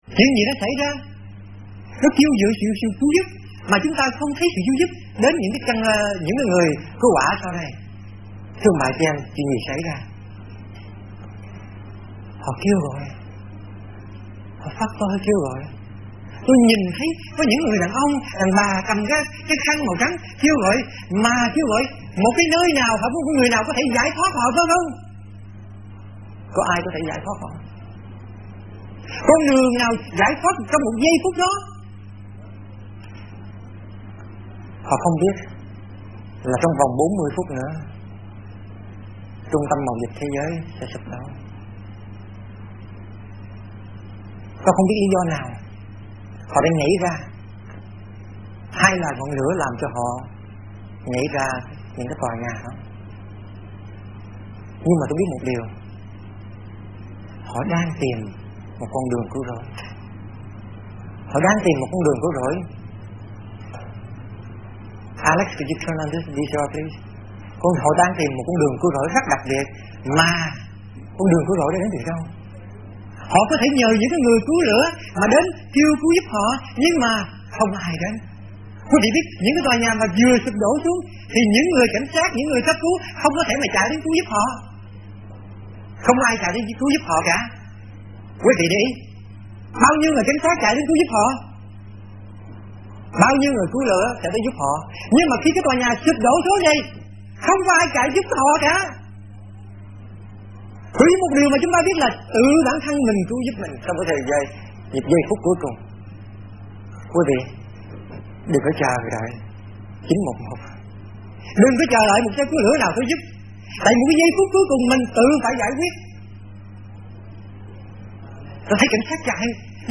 Sermon / Bài Giảng